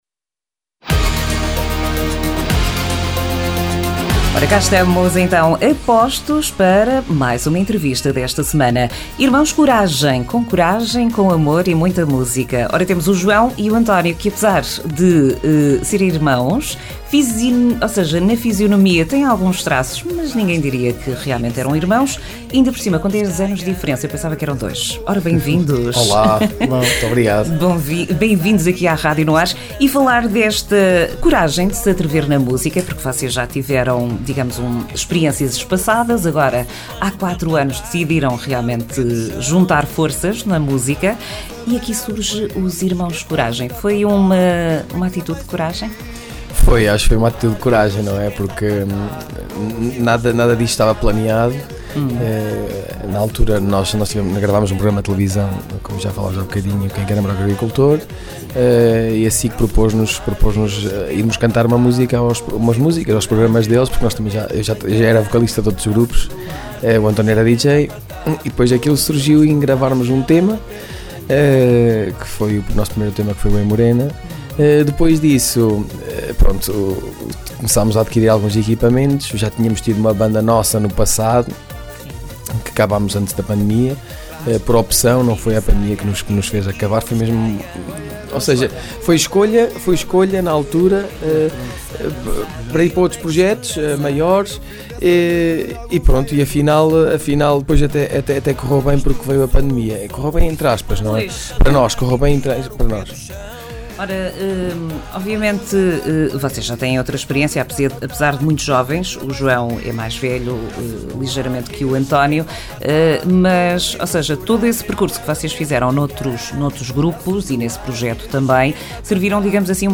Entrevista Irmãos Coragem dia 20 de abril de 2025
ENTREVISTA-IRMAOS-CORAGEM.mp3